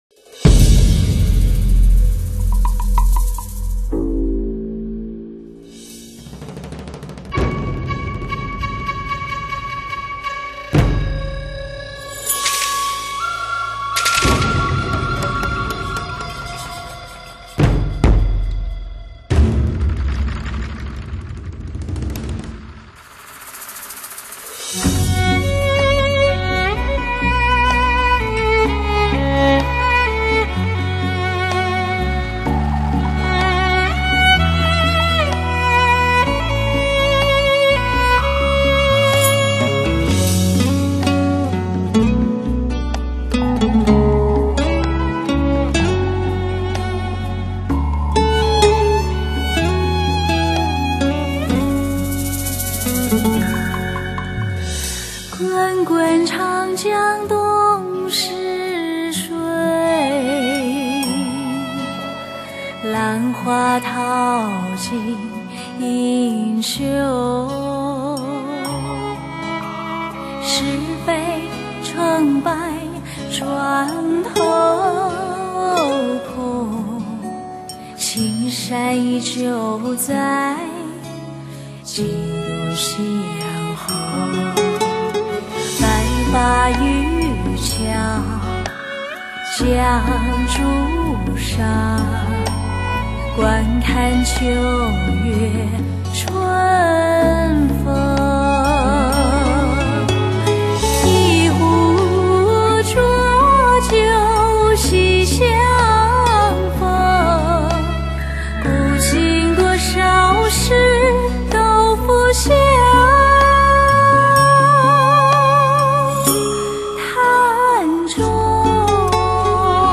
挑战跨度大、音域宽、难度高的男声经典歌曲。
飘逸、纯净、亮丽、超凡脱俗的演唱，将女声那妩媚之美、
柔情之美、英气之美，完美的融为一体。